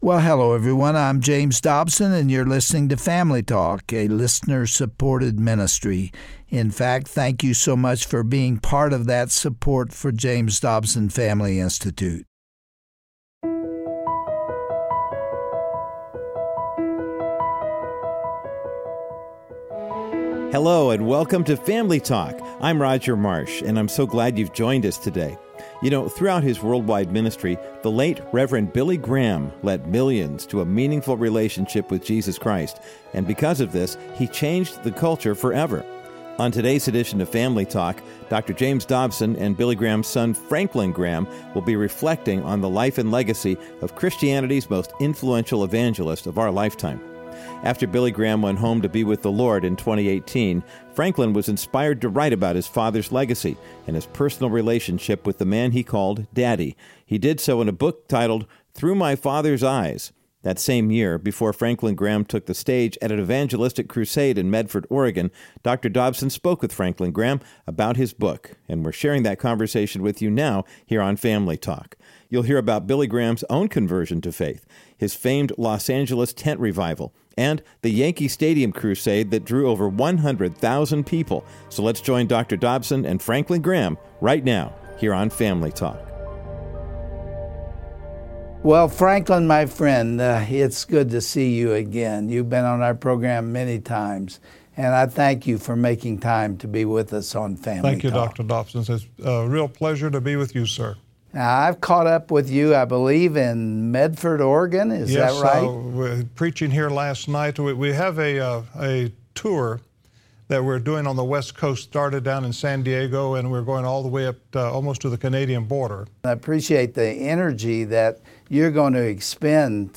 On today’s edition of Family Talk, Dr. James Dobson and Billy’s son, the Reverend Franklin Graham, reflect on the life and legacy of Christianity’s most influential evangelist.
Broadcast